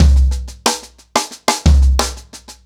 Wireless-90BPM.45.wav